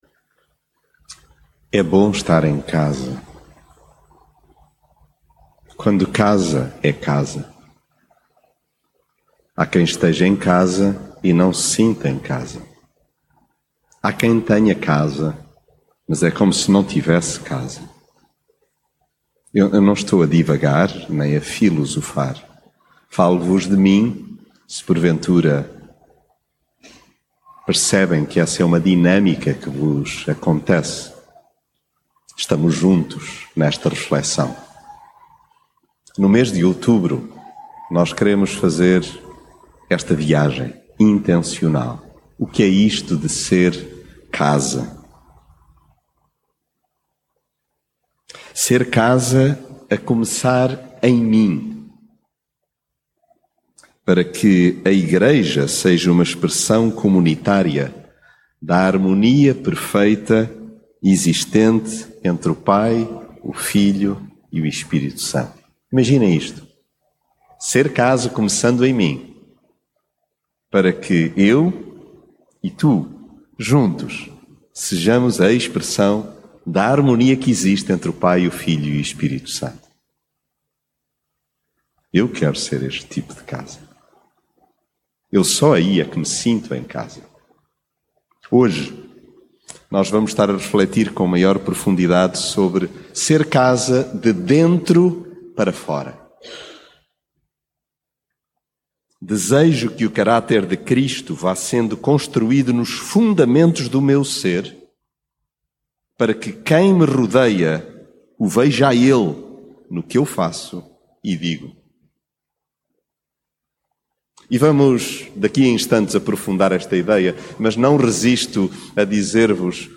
ser casa mensagem bíblica A habitação é um bem de primeira necessidade.